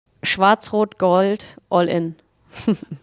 Mit diesem Ruf bringt sich die deutsche Nationalmannschaft vor den WM-Spielen in Stimmung.